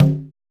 LINN CGA LO.wav